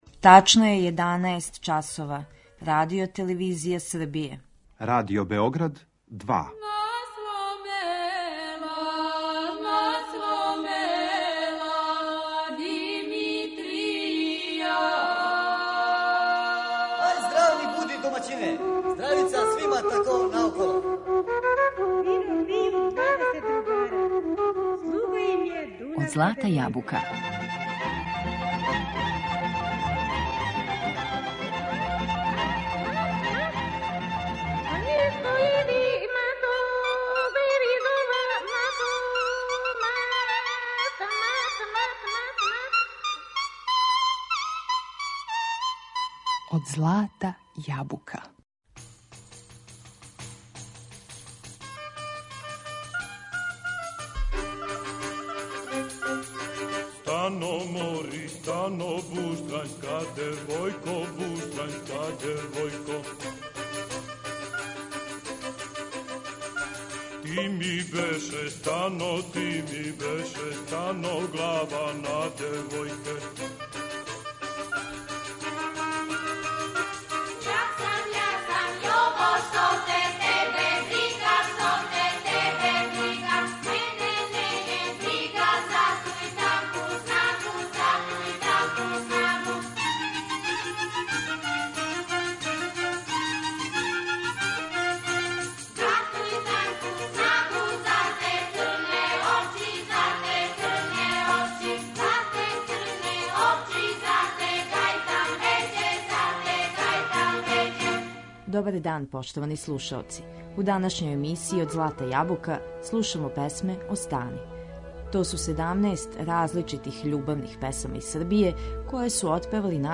У питању је избор од 17 различитих љубавних песама из Србије. Многе од њих забележене су и снимљене у много варијаната, и текстуалних и мелодијских.